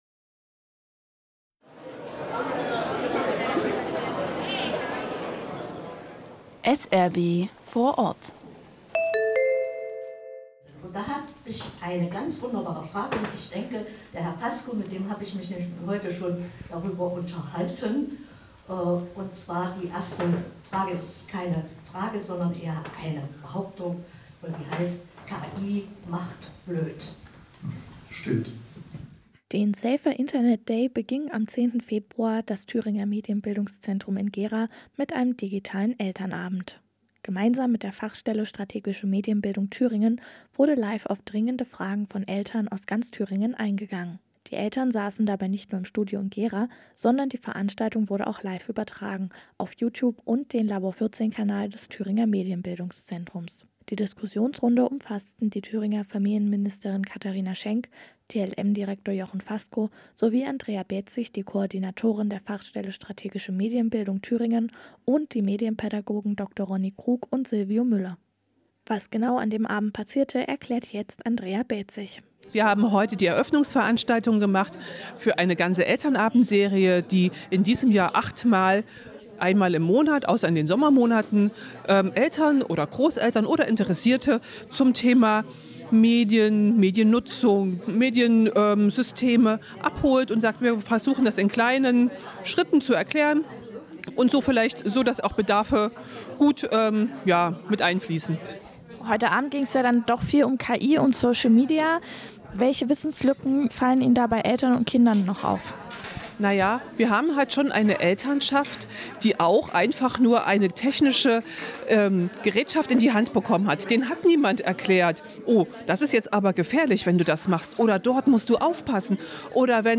Radio-Magazinbeitrag